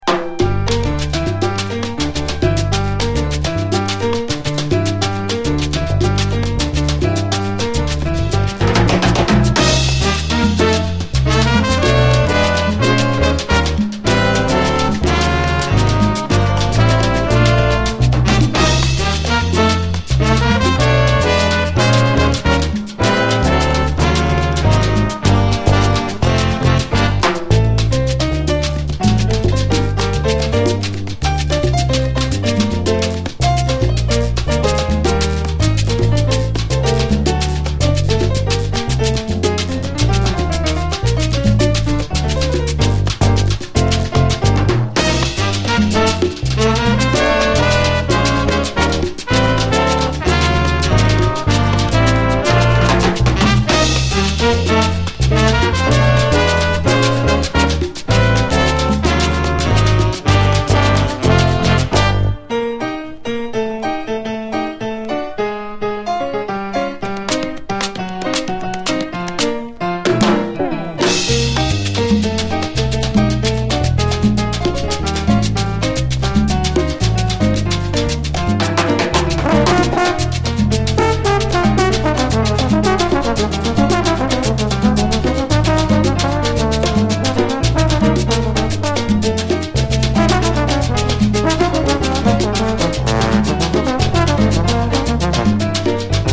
JAZZ, LATIN
遅れて来た80’Sラテン・ジャズ・ピアニスト！